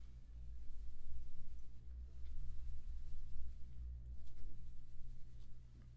39风
描述：风声
Tag: 树木 树叶